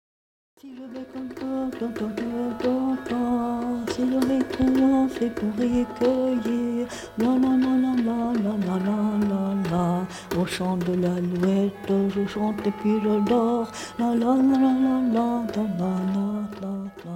Carillon de Champéry: L'Alouette (1) - The skylark (1)